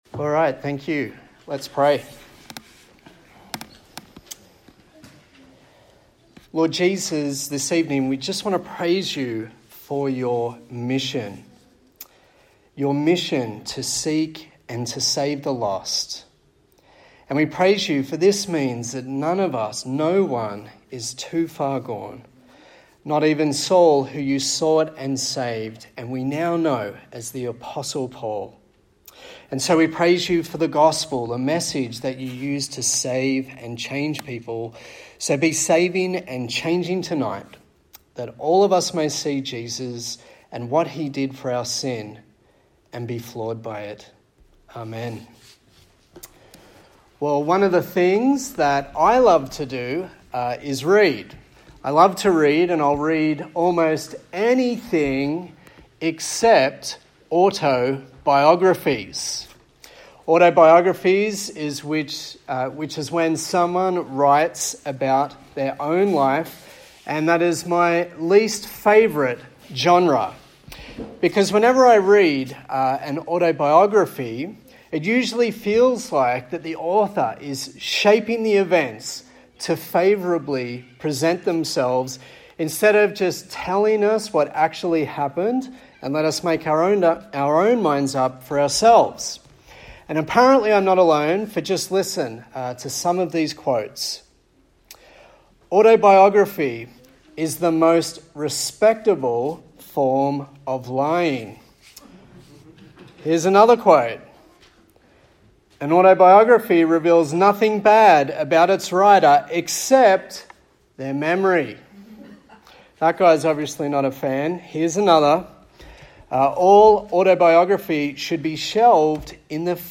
A sermon in the series on the book of Galatians